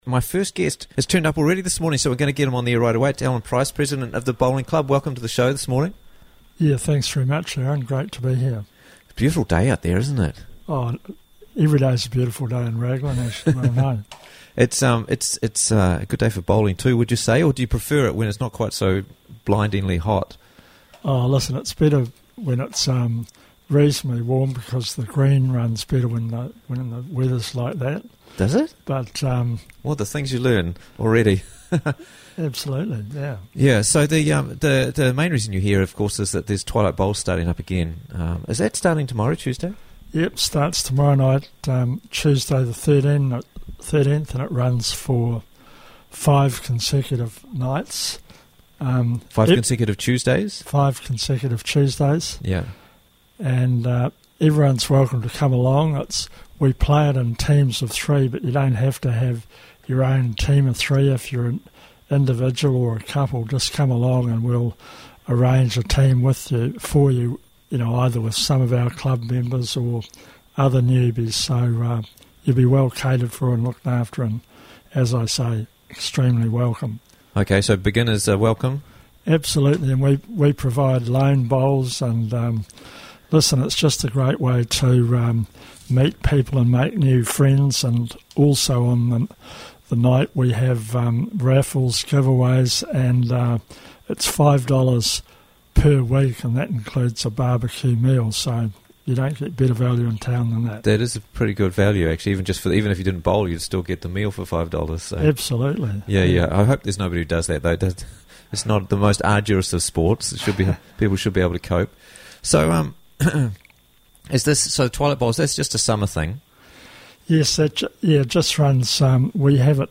Twilight Bowls Starts Tuesday Evening - Interviews from the Raglan Morning Show